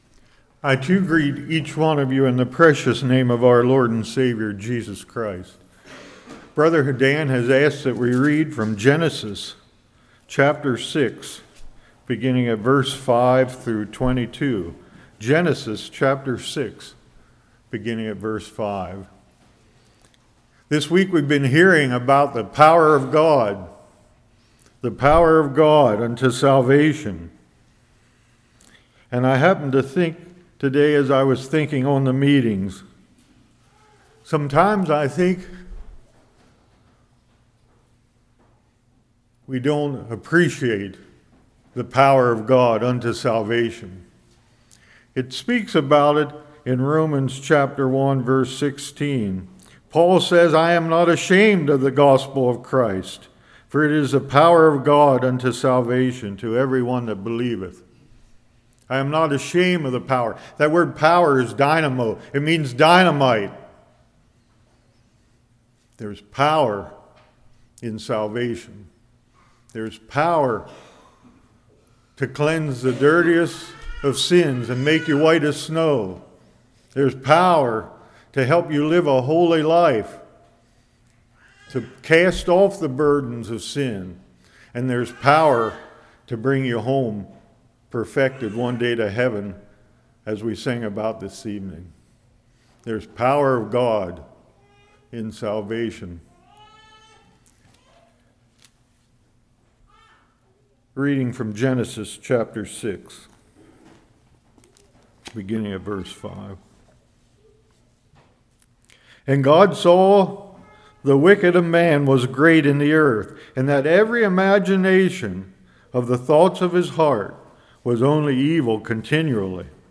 Genesis 6:5-22 Service Type: Revival Noah built an Ark because he had faith.